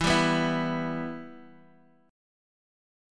Em chord